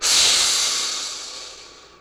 c_croc_atk2.wav